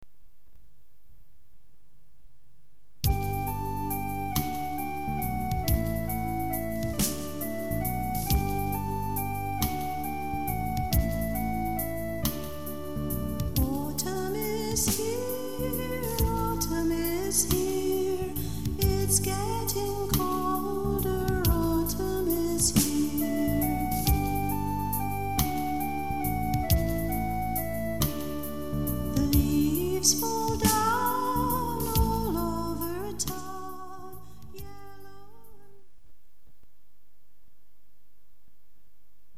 CHILDREN'S SONGS